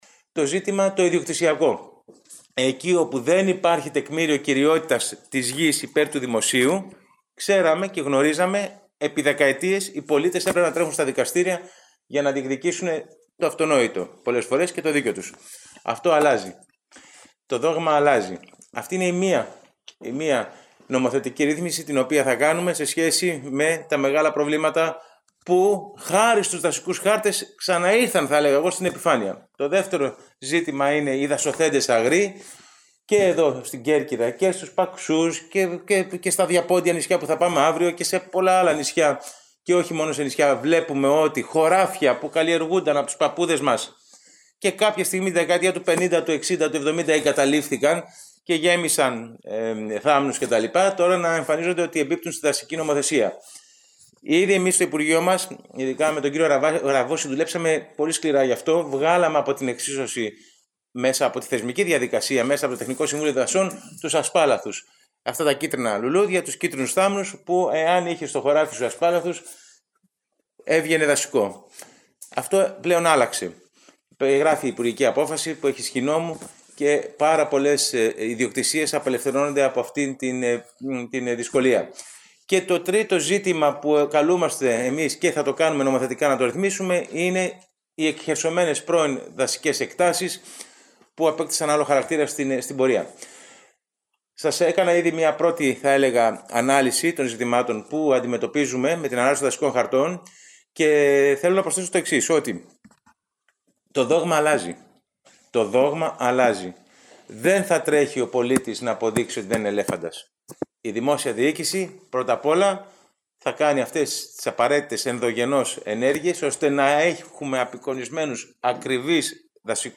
Αυτό δήλωσε χθες ο υφυπουργός αρμόδιος για τους δασικούς χάρτες, Γιώργος Αμυράς, από την Κέρκυρα.